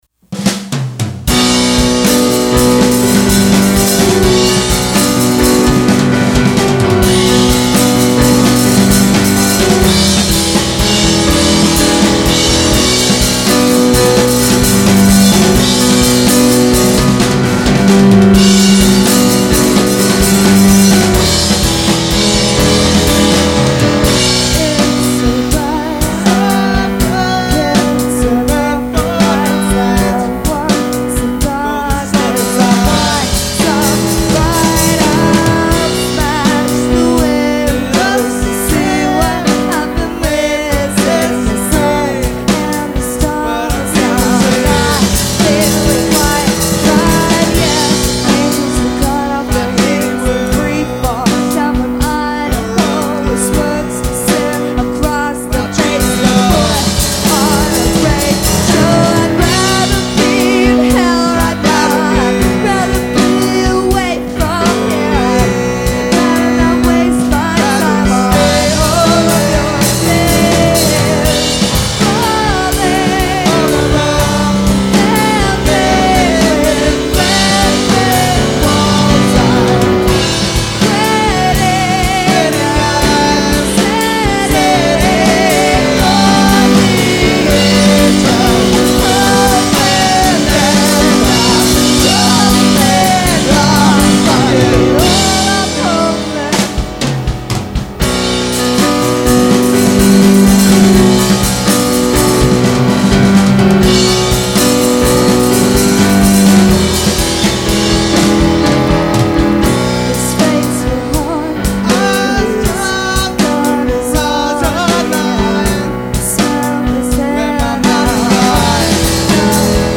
Songs 11-18 recorded live in Olympia, WA, summer 2002.